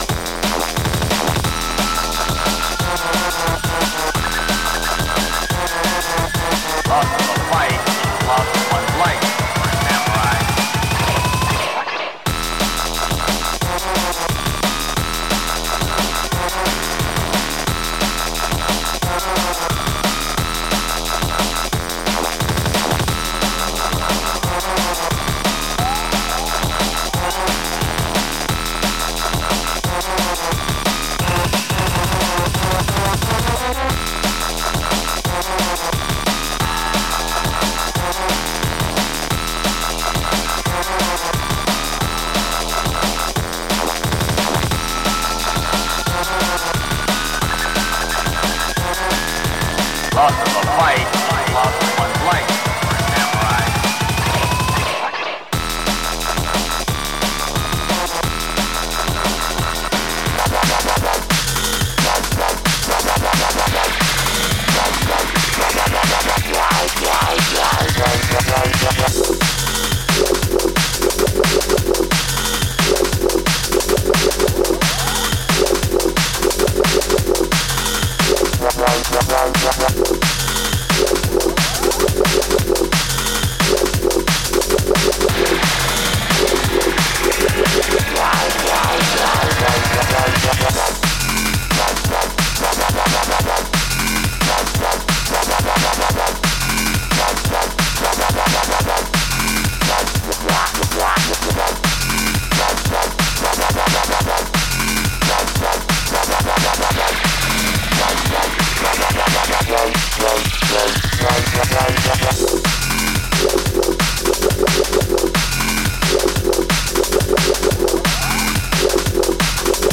Category: Drum N Bass